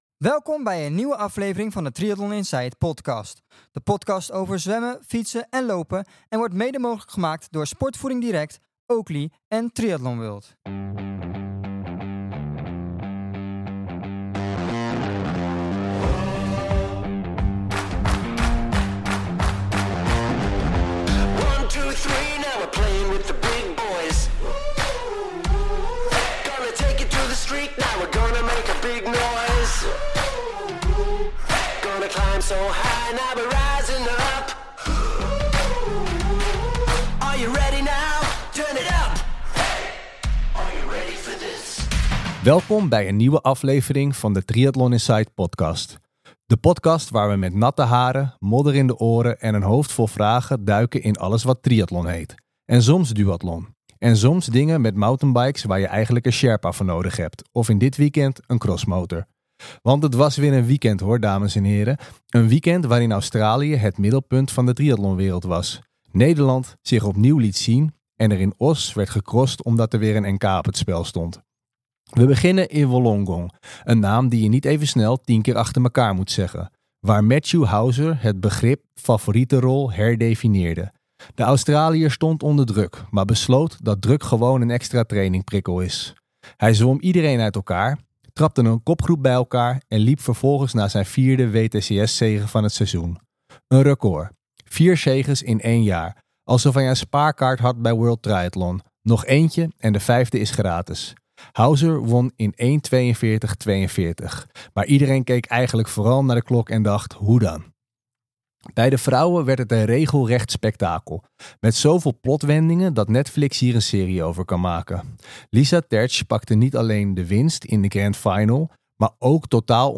In deze aflevering kijken we samen met twee crosspecialisten naar deze discipline die ook bij de triathlon hoort. Wat maakt de cross nou zo mooi en wat maakt het anders dan de andere triathlon disciplines. We hebben het over races materiaal en nog veel meer.